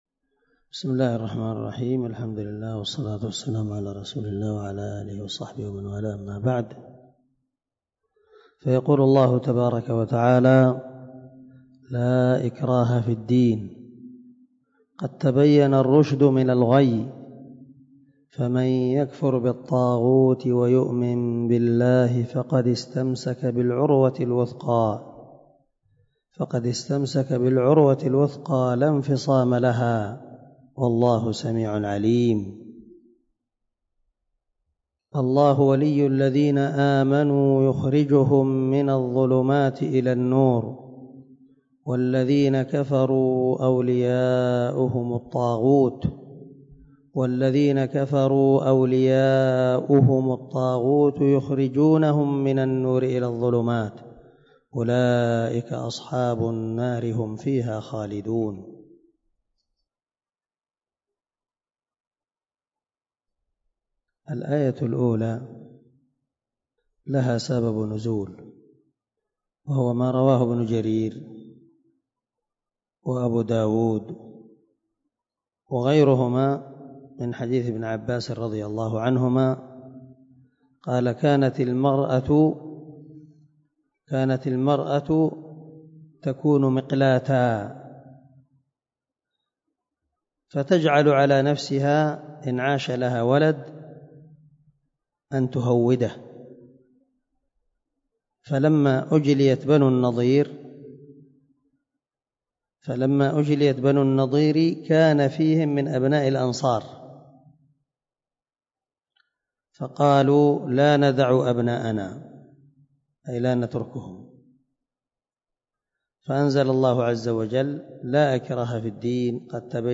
135الدرس 125 تفسير آية ( 256 – 257 ) من سورة البقرة من تفسير القران الكريم مع قراءة لتفسير السعدي